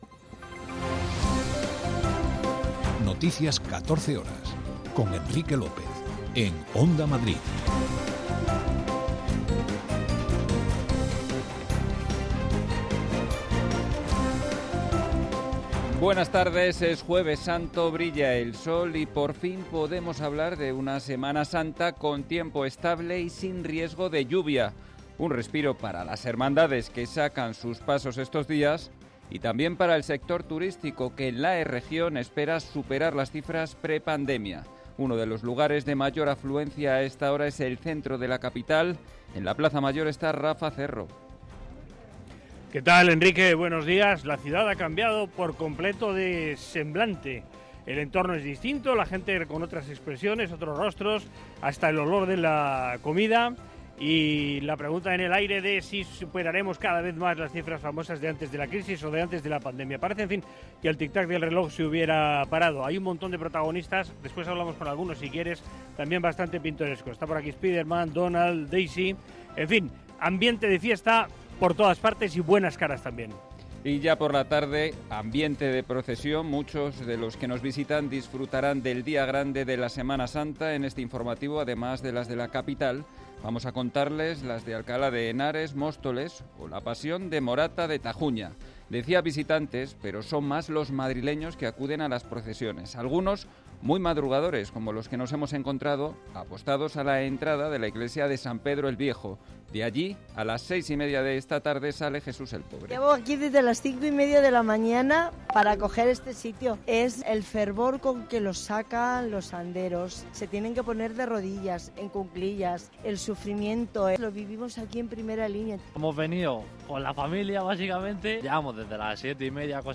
Noticias 14 horas 06.04.2023
en España y en el Mundo. 60 minutos de información diaria con los protagonistas del día, y conexiones en directo en los puntos que a esa hora son noticia